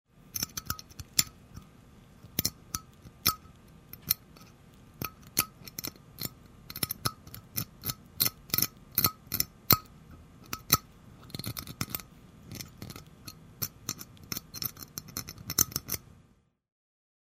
Звук стоматологических инструментов в полости рта